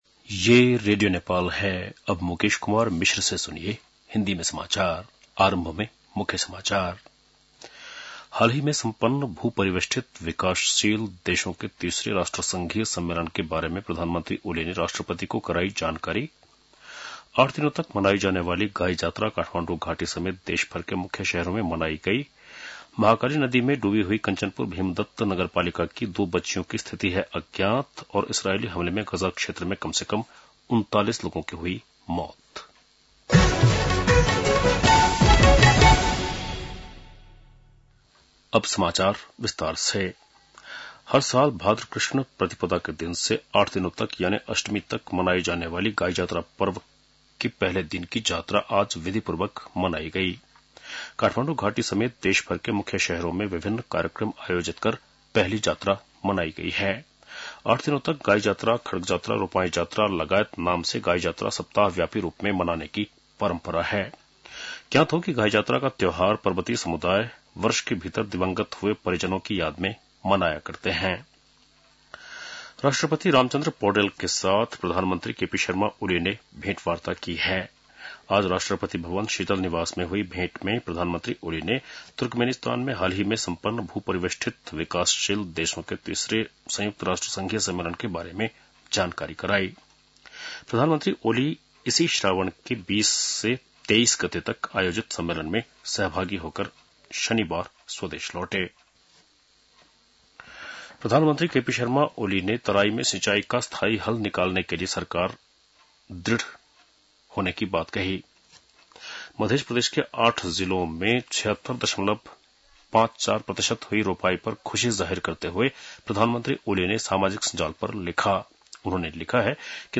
बेलुकी १० बजेको हिन्दी समाचार : २५ साउन , २०८२
10-pm-Hindi-news-4-25.mp3